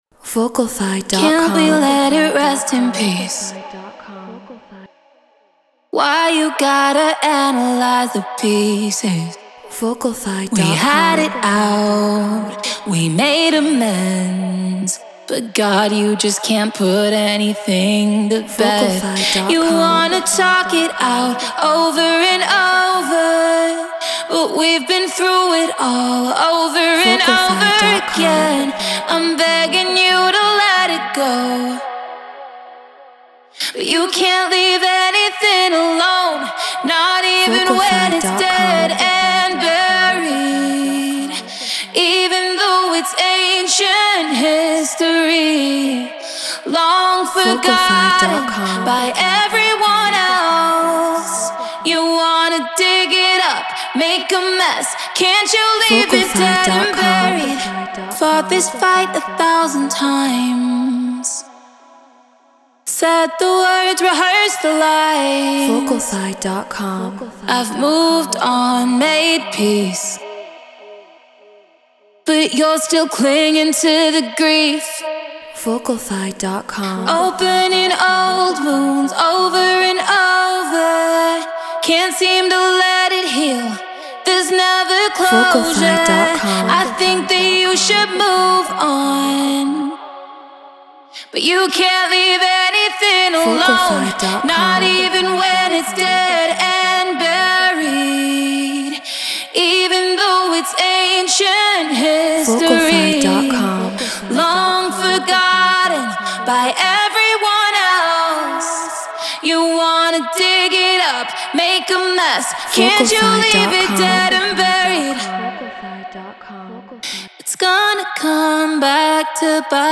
Pop 100 BPM Emaj
Human-Made